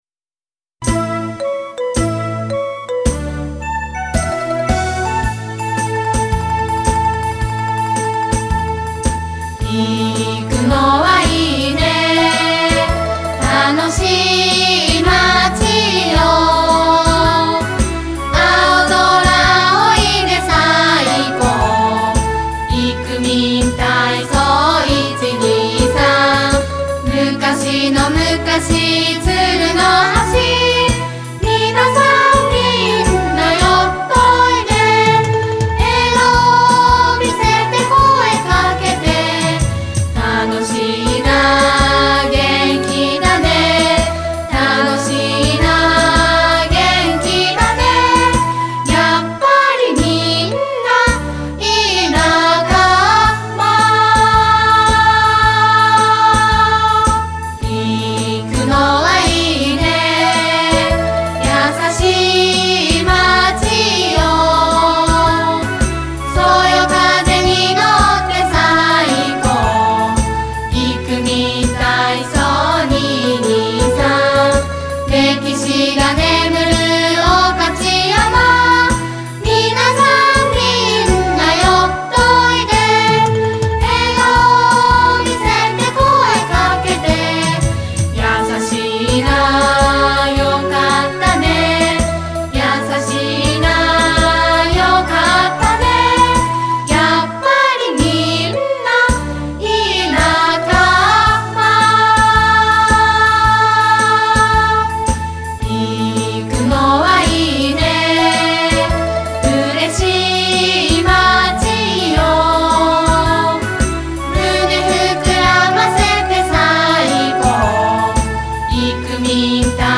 作詞・角田　富雄　／唄・生野少年少女合唱団